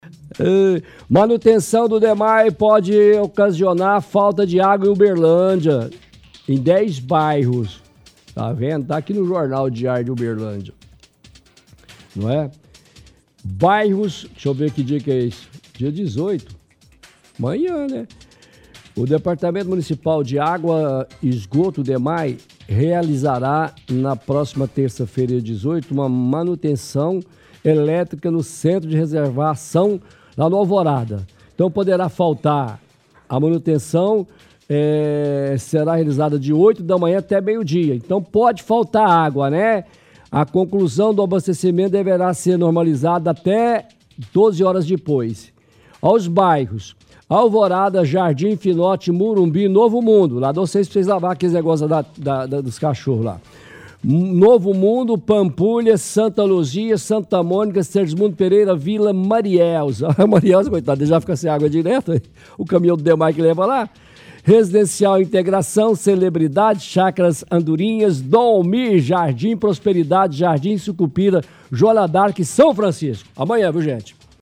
lê reportagem do Diário de Uberlândia de manutenção do Dmae que acontece amanhã.